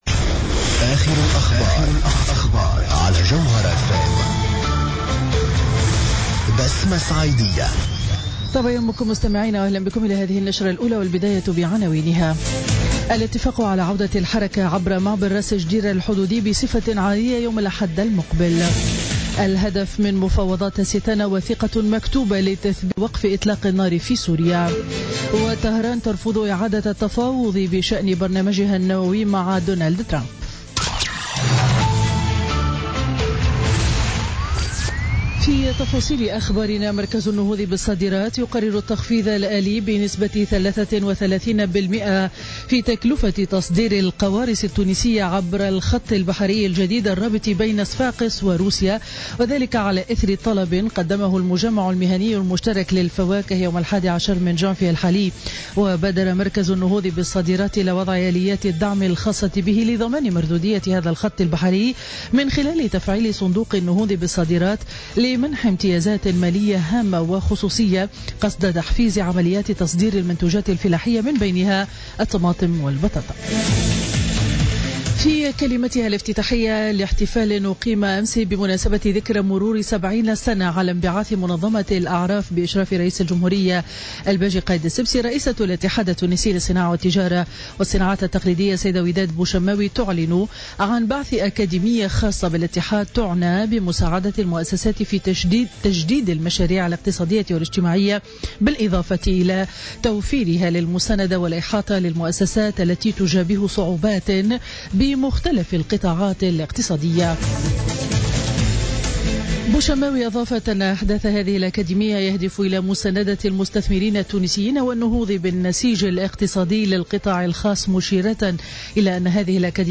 نشرة أخبار السابعة صباحا ليوم الأربعاء 18 جانفي 2017